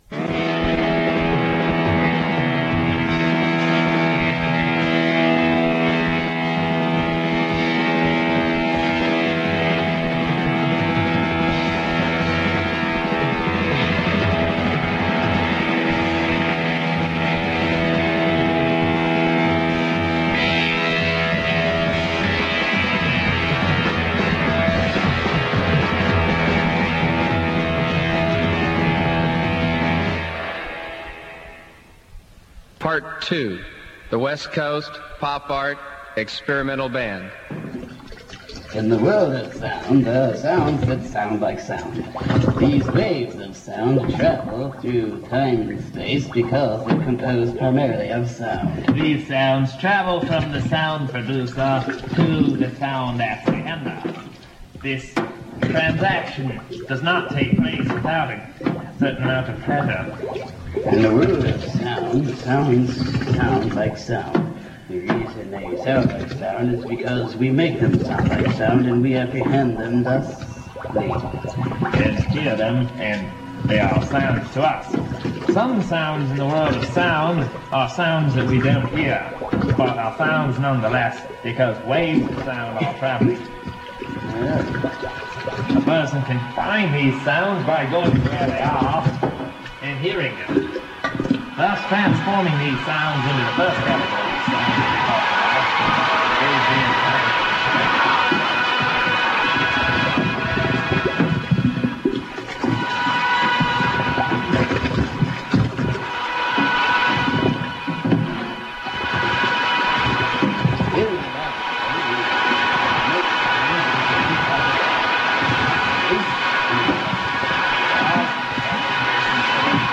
The Universal Cosmic Groove was a total freeform dada-anarchy radio show on 89.3 WAMH FM (Amherst College radio - Amherst, MA) Saturday Nights from 1992-1994.
Preparation consisted only of vague themes and a few featured selections.
At worst, it sounded like tuning into six stations on the same frequency. At best, the random elements would converge into a semiotic symbiosis, with each disc or tape informing and blending with the others to create a brain-blasting psychedelic montage.